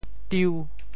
Details of the phonetic ‘diu5’ in region TeoThew
IPA [tiu]